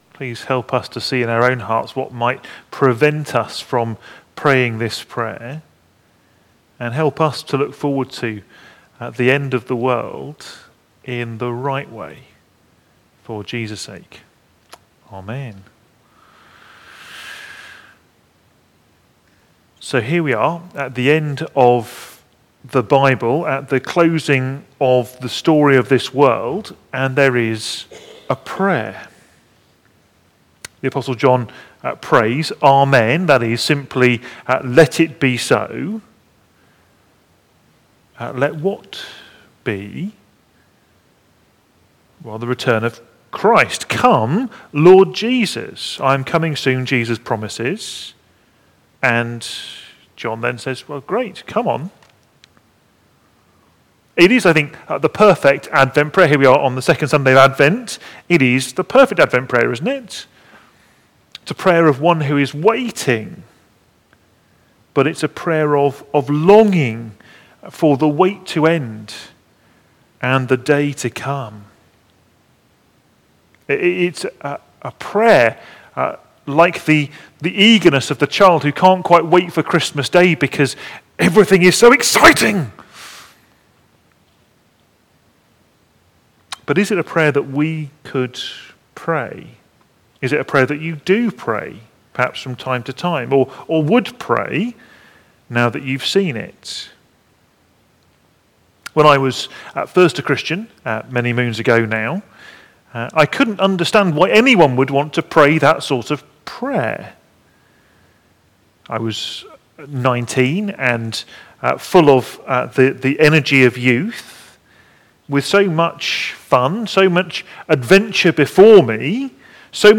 Media Library We record sermons from our Morning Prayer, Holy Communion and Evening services, which are available to stream or download below.
Passage: Revelation 22:20-21 Series: Prayers of the Church Theme: Sermon Search